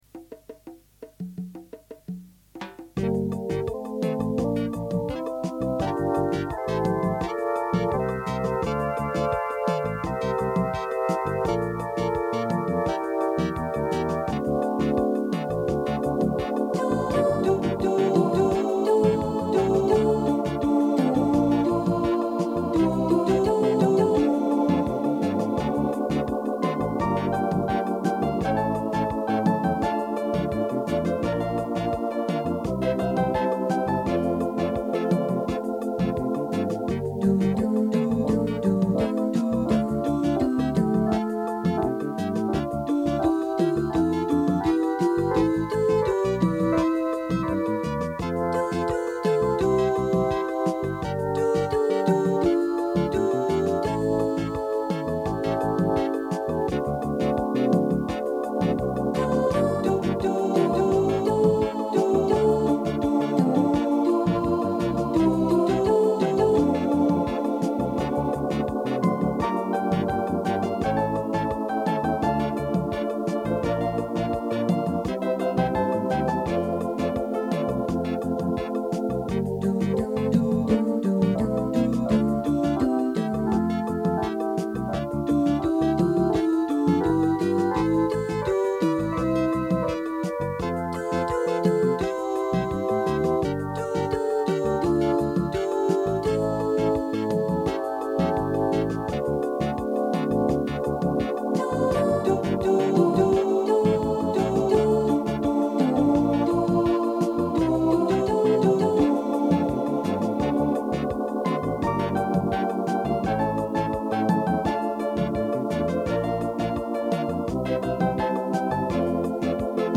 De enige muziekweergave bestond toendertijd uit een cassette band opname van live performance op mijn Yamaha Electone HC-2 orgel.
Dank zij een zeer nuttige (MIDI-thru) "bug" in de toenmalige interface was ik in staat om de HC-2 samen te laten spelen met de Voice Oohs van een Roland SC-7 Sound Canvas, die op mijn computer was aangesloten ten behoeve van General Midi.
MP3 opname geworden van een volautomatische uitvoering van het liedje.